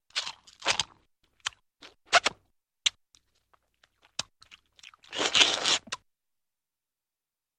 • Качество: высокое
Человек чавкает за столом с тарелкой спагетти